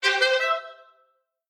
fanfare.mp3